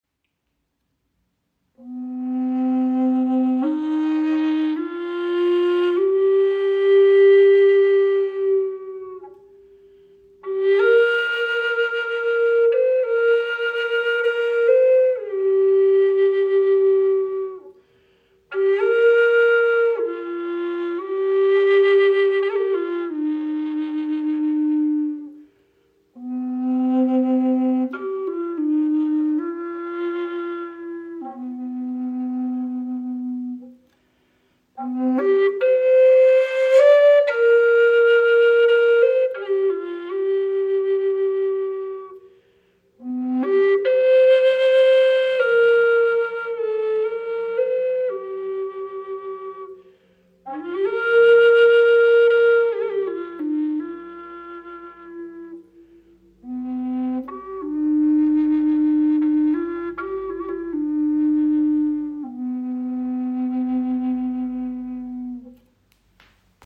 Diese 432 Hz Chakra Bass Flöte in tiefem C aktiviert das Wurzelchakra und schenkt Dir einen kraftvollen, erdenden Klang aus handgefertigtem Walnussholz.